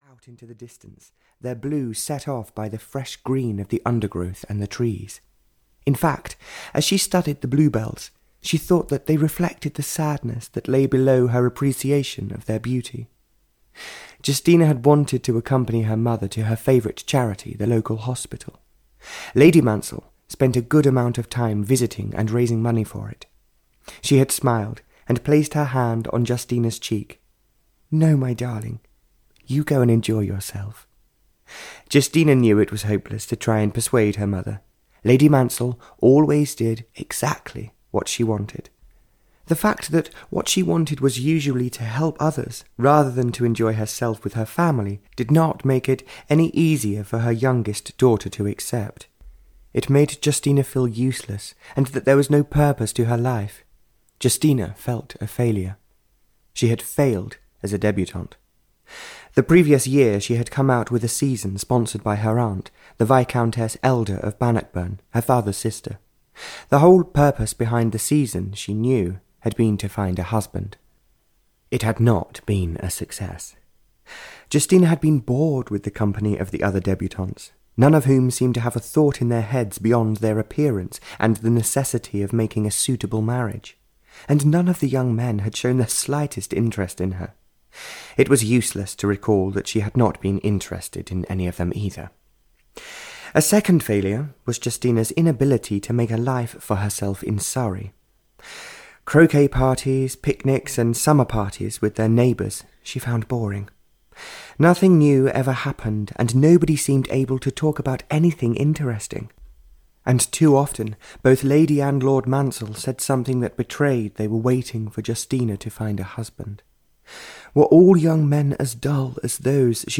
Touching the Stars (EN) audiokniha
Ukázka z knihy